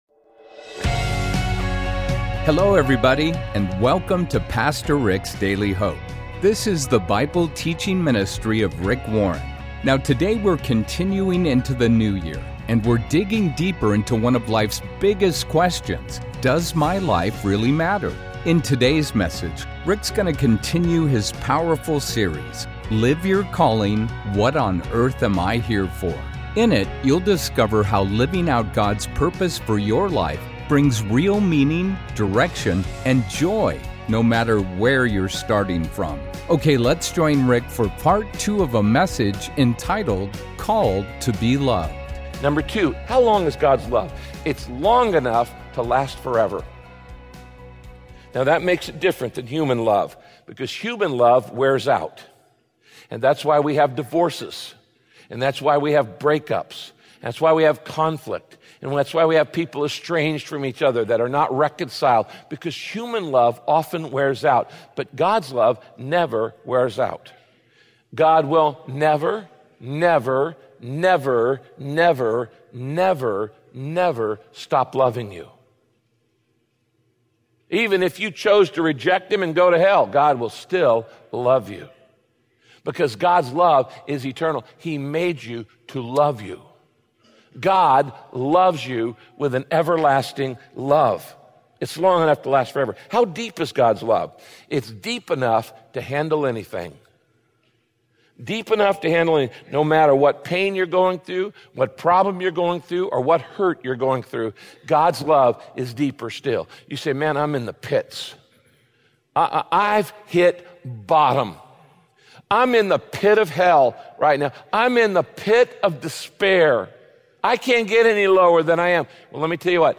In this broadcast, Pastor Rick teaches why you have to begin with God's nature to be able…